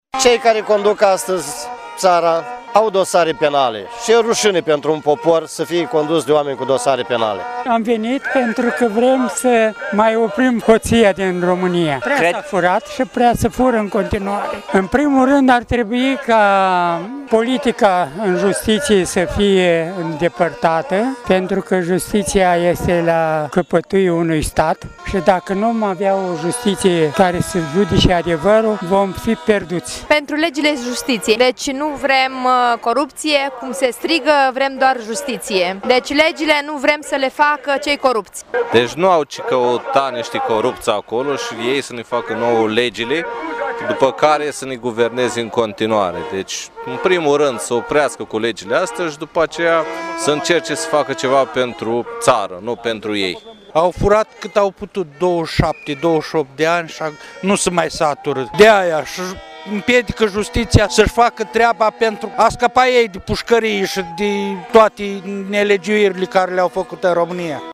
20-ian-ora-20-vox-Iasi.mp3